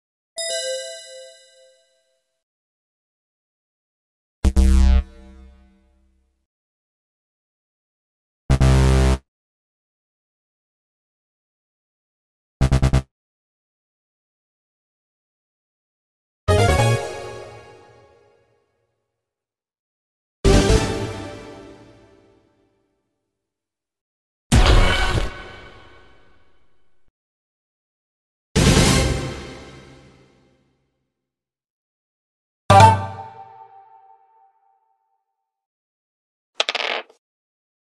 Sonneries » Sons - Effets Sonores » bruitage quiz